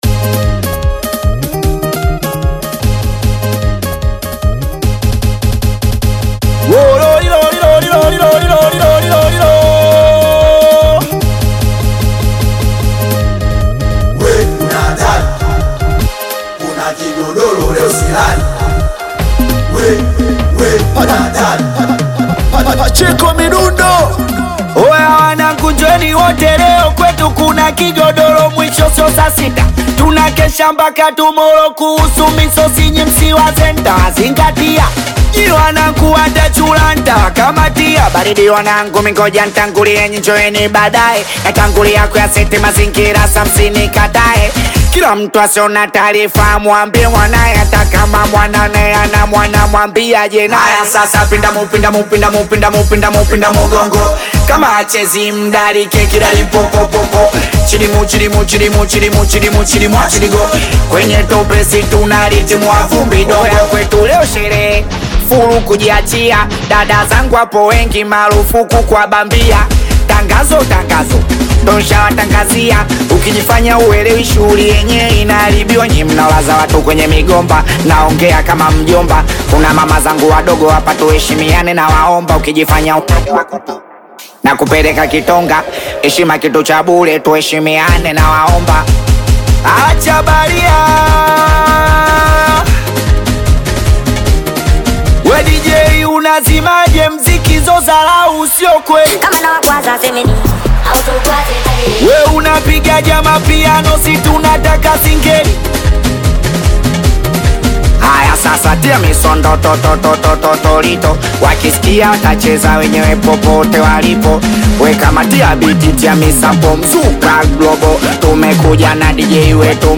Singeli music track
Tanzanian Bongo Flava Singeli
Singeli song